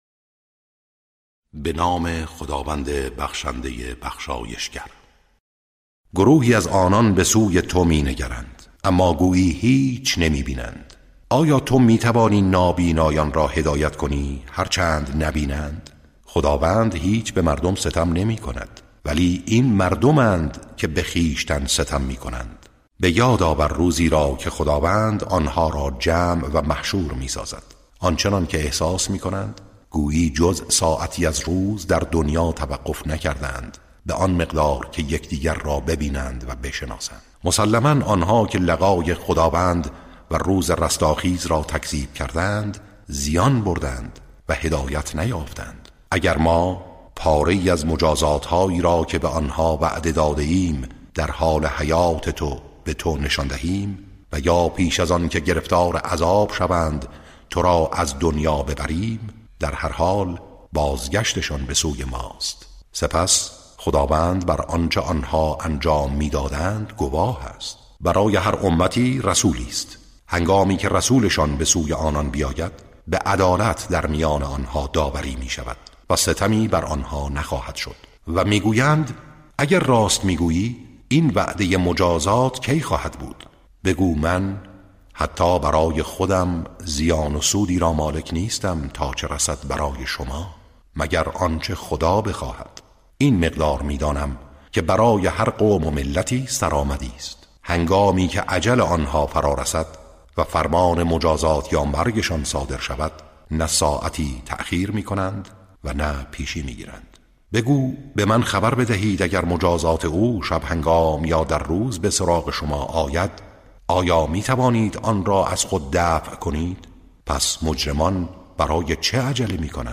ترتیل صفحه ۲۱۴ سوره مبارکه یونس(جزء یازدهم)